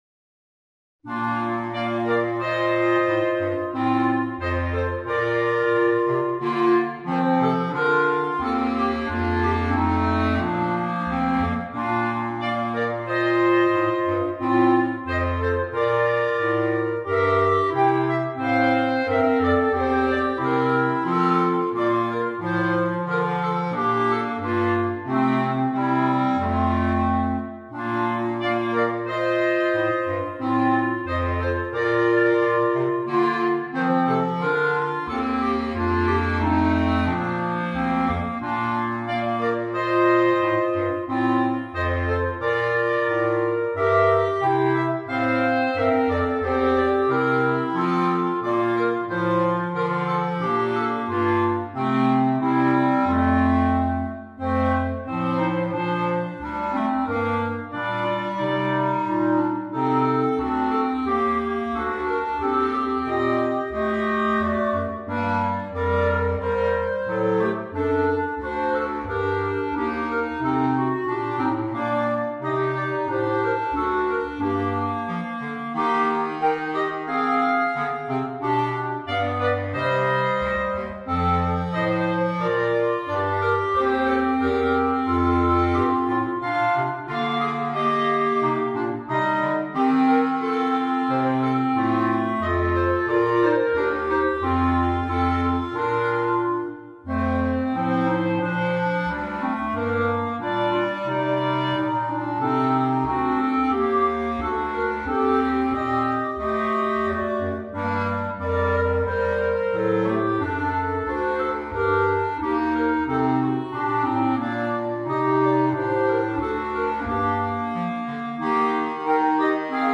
for Clarinet Quartet